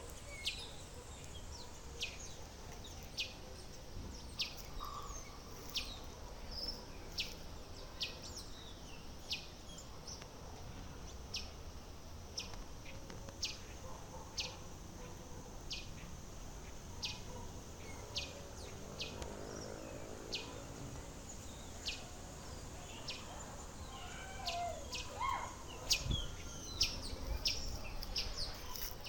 Sanhaçu-de-fogo (Piranga flava)
Nome em Inglês: Hepatic Tanager
Localidade ou área protegida: Del Viso
Condição: Selvagem
Certeza: Fotografado, Gravado Vocal